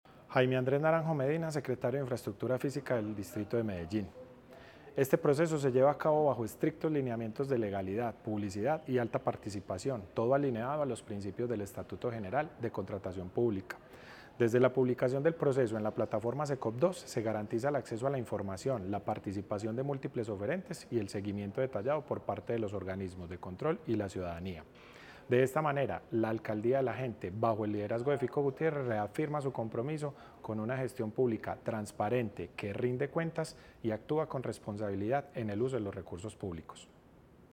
La ejecución de este contrato permitirá desarrollar siete proyectos viales, ocho intervenciones de estabilización y algunas obras complementarias. Declaraciones del secretario de Infraestructura Física, Jaime Andrés Naranjo Medina.
Declaraciones-del-secretario-de-Infraestructura-Fisica-Jaime-Andres-Naranjo-Medina.-Estudios-y-disenos-de-vias.mp3